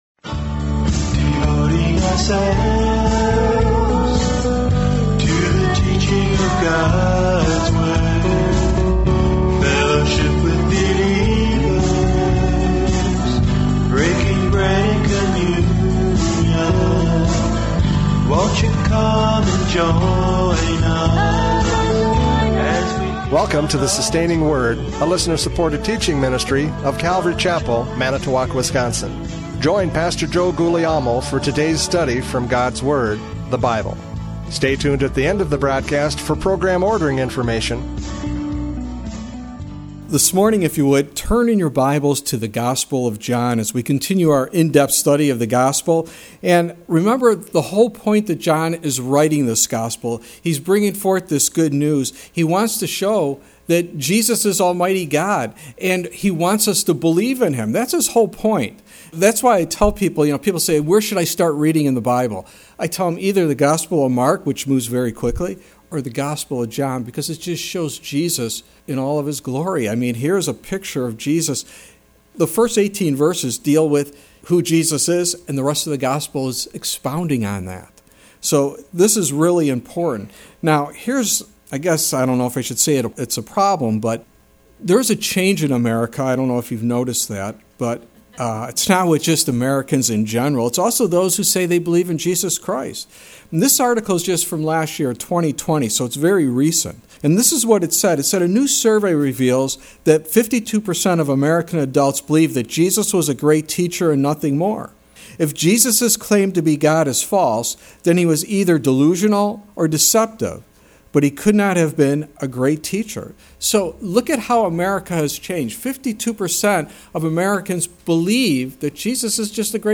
John 1:14-18 Service Type: Radio Programs « John 1:6-13 The Witness and Response!